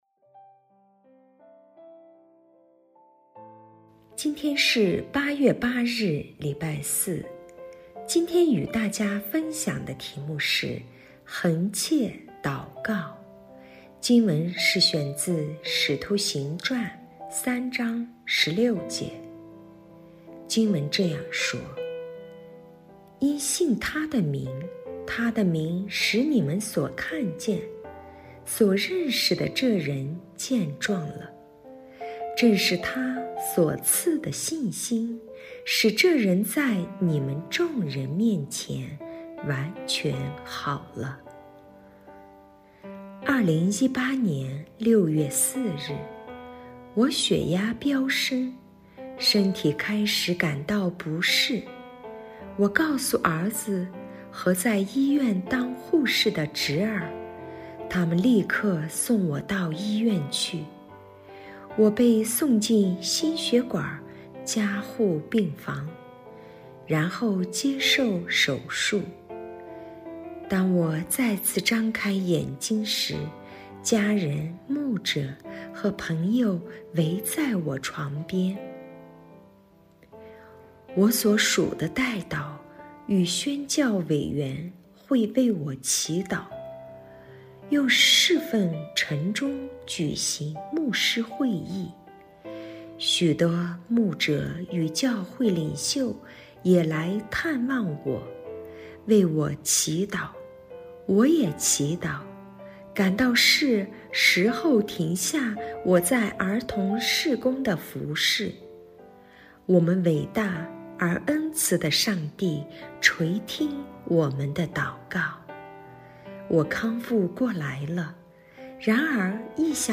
循道衞理聯合教會香港堂 · 錄音佈道組 Methodist Outreach Programme
普通話2024年8月8日恆切禱告